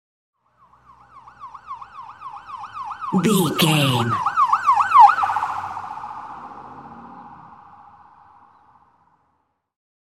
Ambulance Ext Passby Short Stress Siren
Sound Effects
urban
chaotic
dramatic